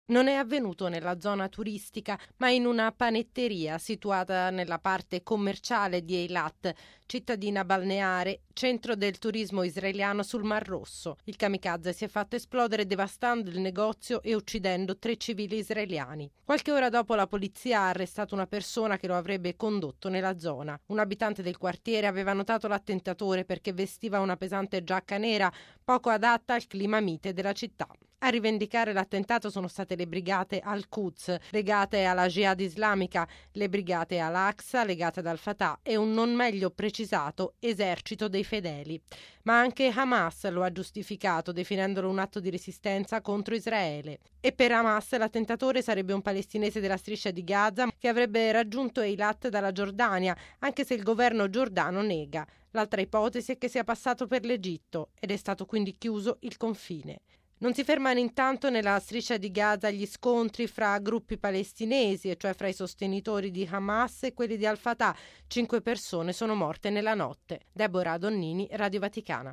E il premier israeliano Olmert ha promesso una lotta senza tregua ai terroristi: erano nove mesi che non vi erano attentati sul suolo israeliano. Il servizio